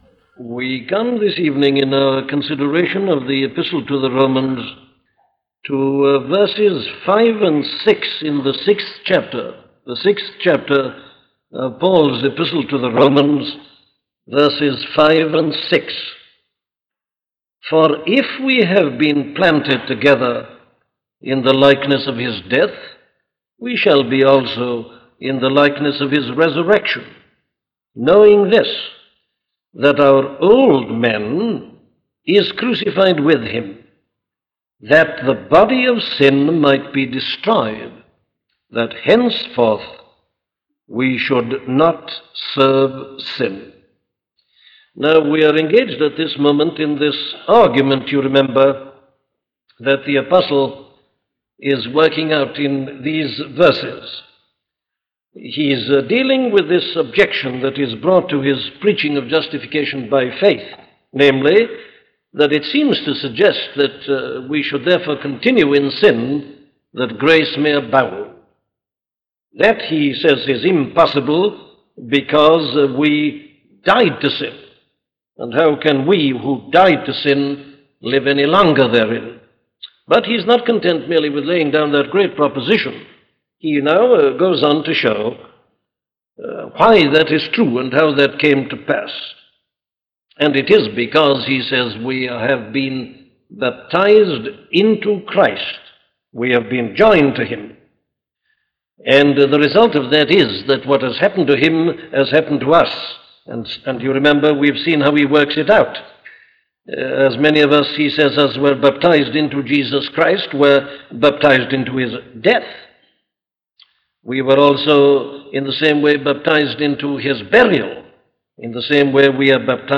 The Old Man Was Crucified - a sermon from Dr. Martyn Lloyd Jones